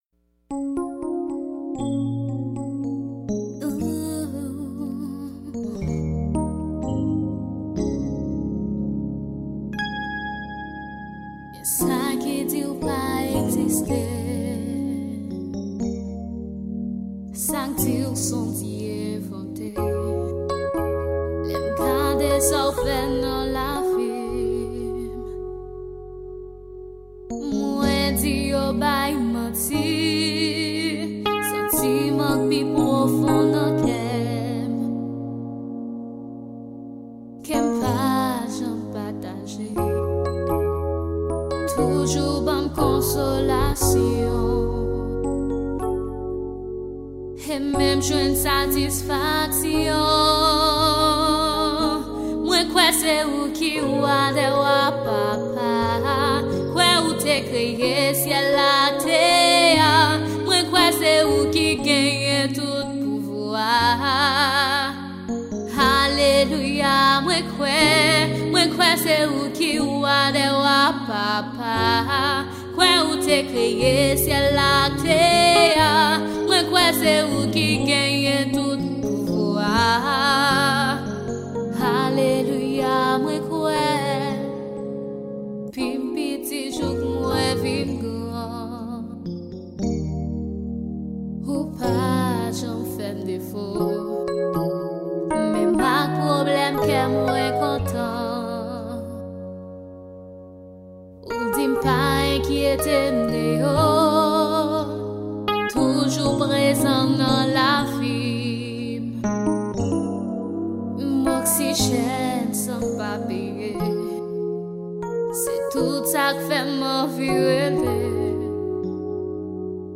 Genre: Evangile.